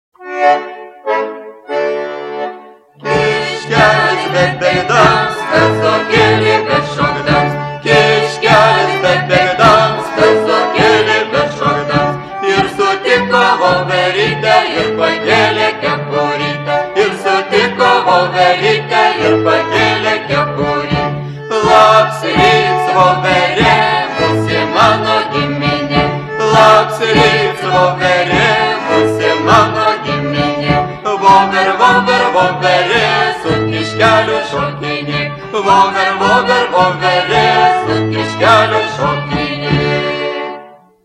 Paired dances